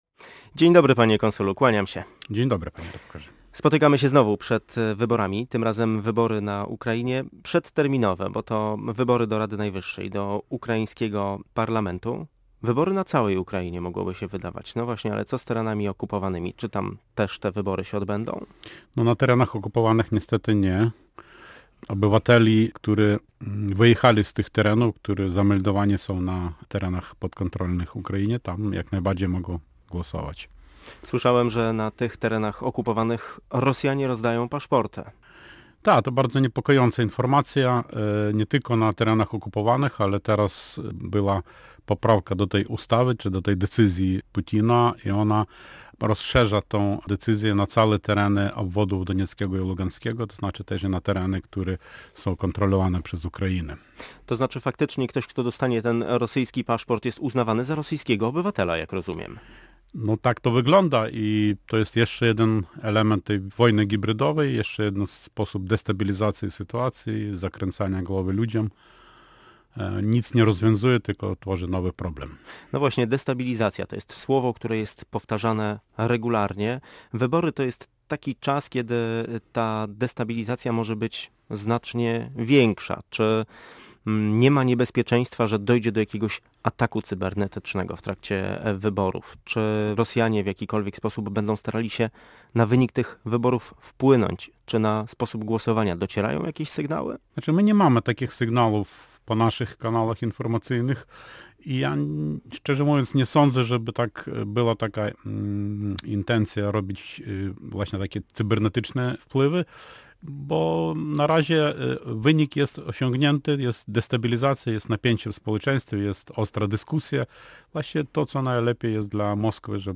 Lew Zacharczyszyn, konsul Ukrainy w Gdańsku.